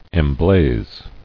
[em·blaze]